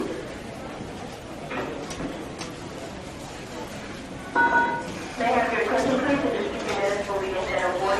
Free SFX sound effect: Airplane Takeoff.
Airplane Takeoff
548_airplane_takeoff.mp3